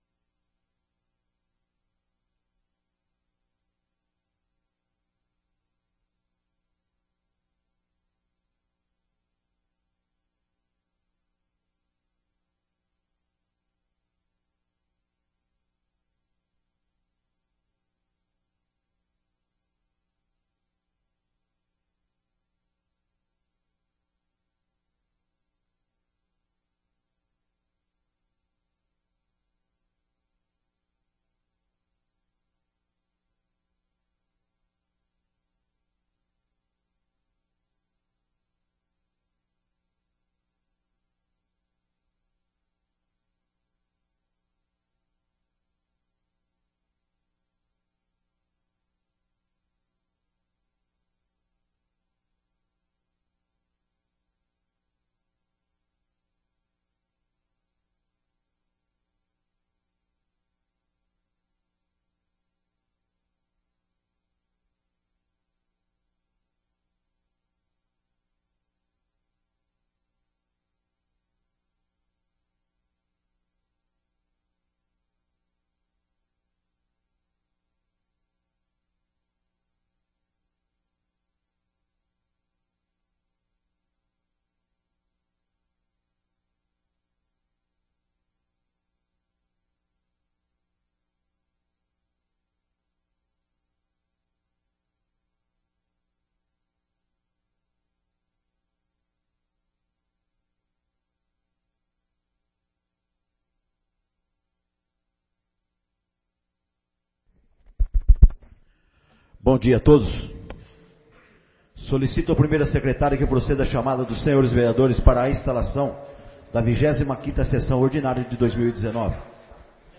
25ª Sessão Ordinária de 2019